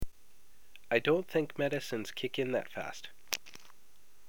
今日の発音